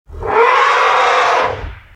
spring.mp3